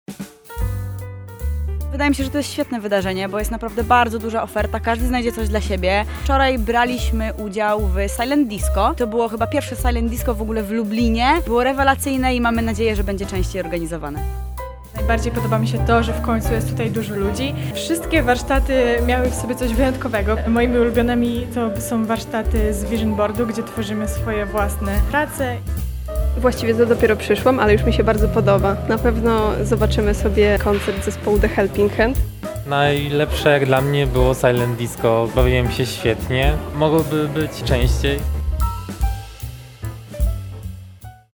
O emocjach towarzyszących spotkaniu rozmawialiśmy z uczestnikami: